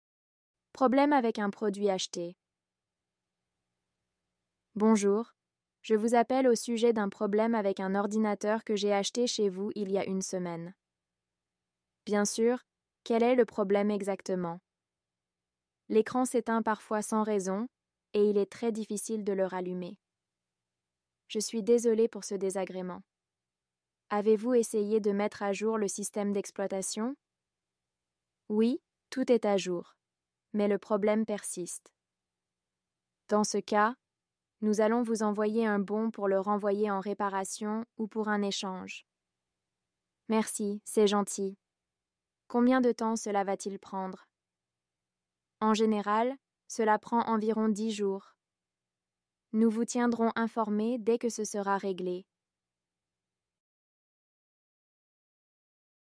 Dialogue B1 : un client appelle le service client pour signaler un problème avec un ordinateur acheté une semaine plus tôt.